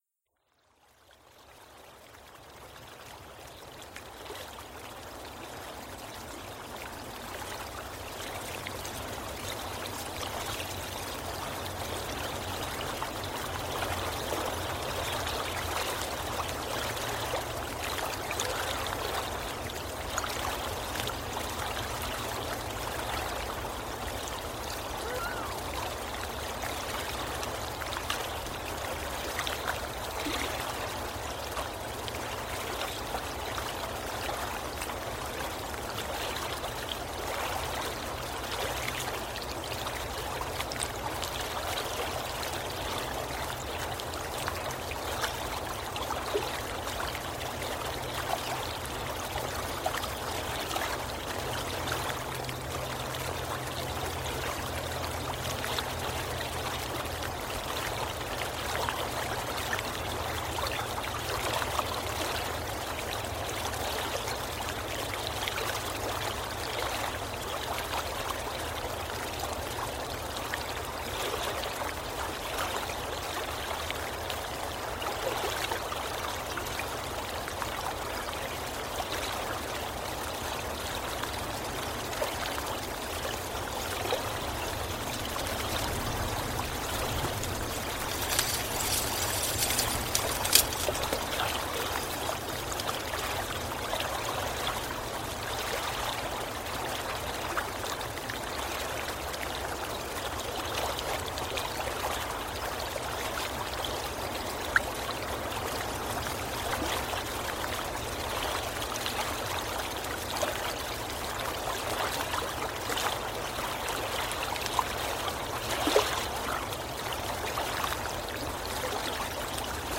Bubbling_Brook.mp3